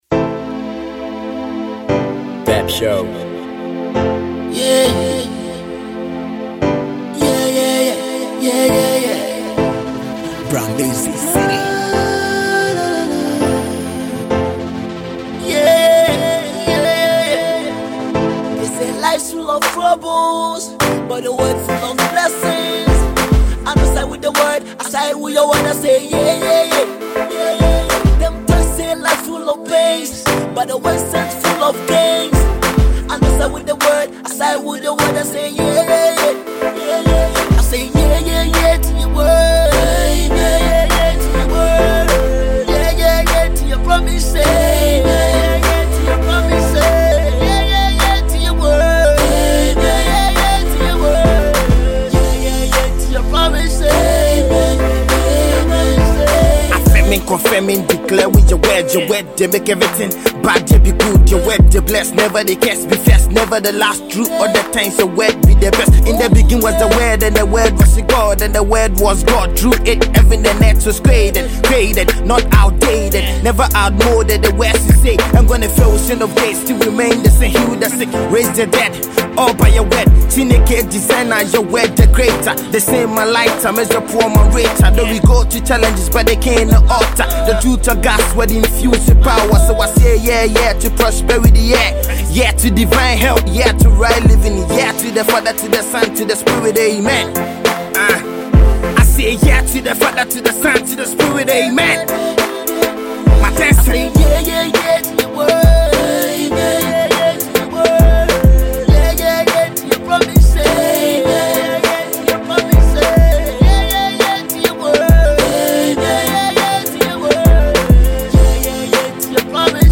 vibrant gospel music group